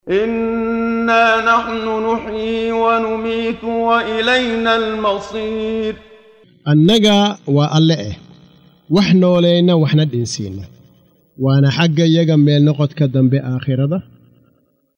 Waa Akhrin Codeed Af Soomaali ah ee Macaanida Suuradda Qaaf oo u kala Qaybsan Aayado ahaan ayna la Socoto Akhrinta Qaariga Sheekh Muxammad Siddiiq Al-Manshaawi.